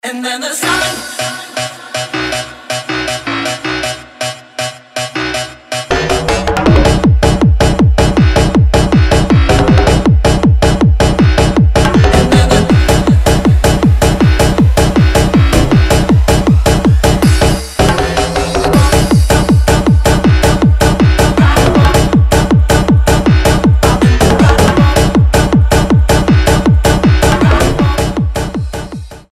• Качество: 320, Stereo
ритмичные
веселые
EDM
энергичные
bounce